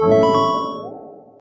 Techmino/media/effect/chiptune/win.ogg at 89134d4f076855d852182c1bc1f6da5e53f075a4
win.ogg